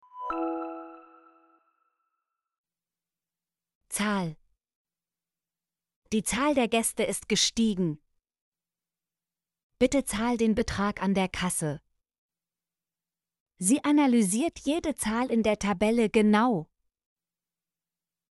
zahl - Example Sentences & Pronunciation, German Frequency List